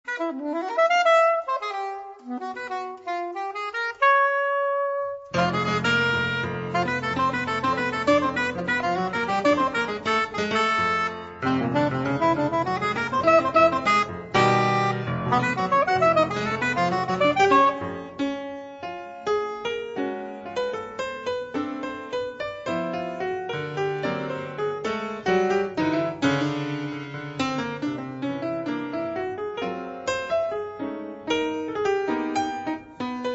piano
soprano saxophone